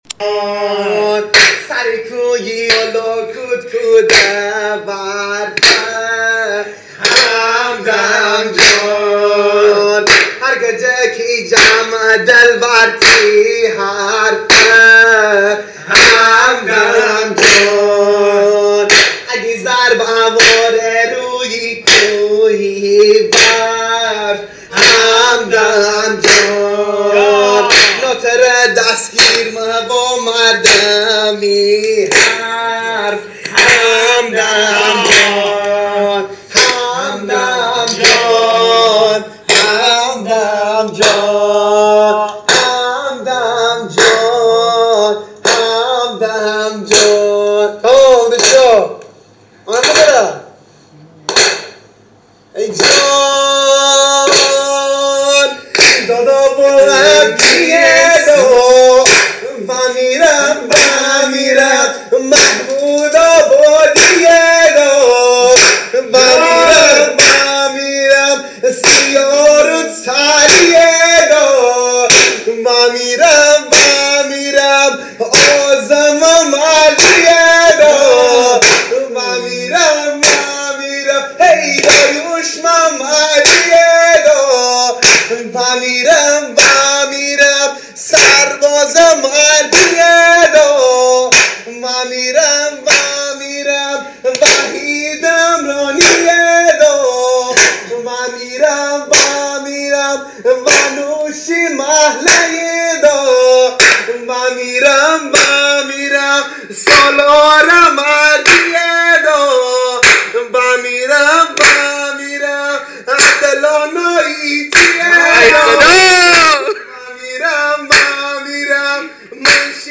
دانلود آهنگ جدید تک دست
آهنگ شاد , خوانندگان مازنی